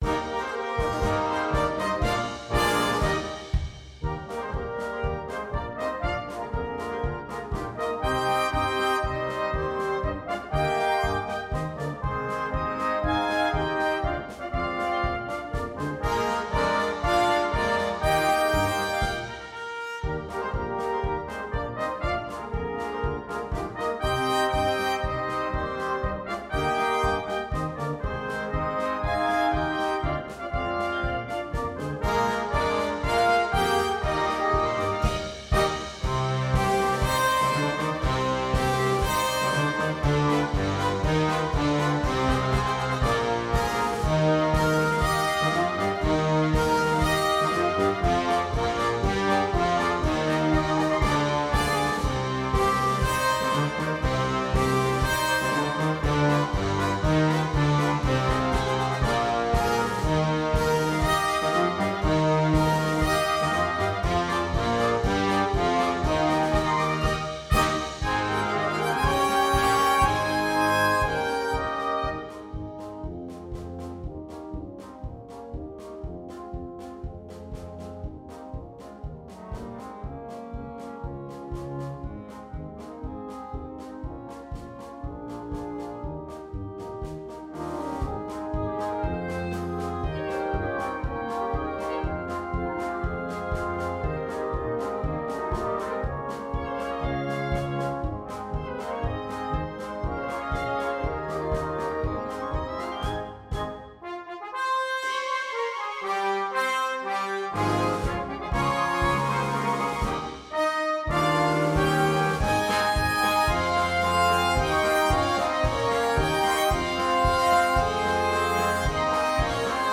2. Egerlander-style band
Full Band
without solo instrument
March